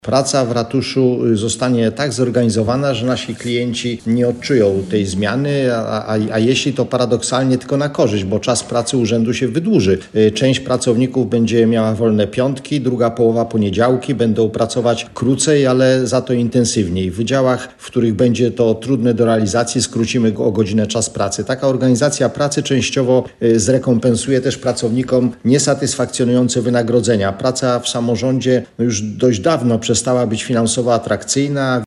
– mówi burmistrz i dodaje, że problem wakatów na stanowiska pracy, które do tej pory cieszyły się małym zainteresowaniem, zniknął z dnia na dzień.